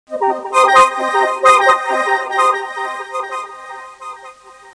keyboard.mp3